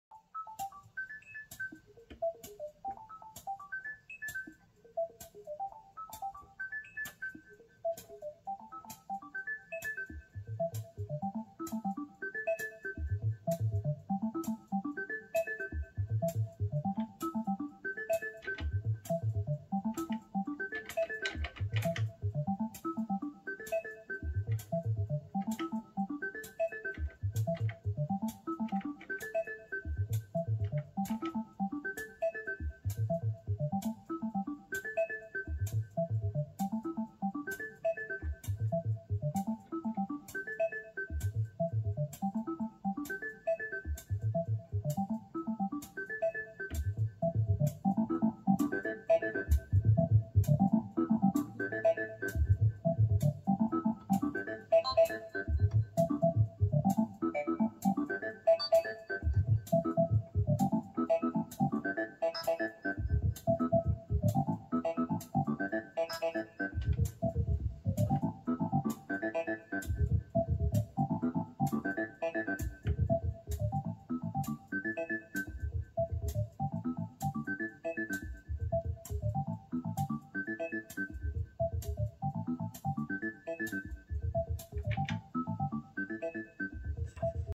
Analog keys, test patch FDB osc1 and filter resonance. Hold + arpeggio 15/16+ AK HH, osc2 fm LFO 1x LFO2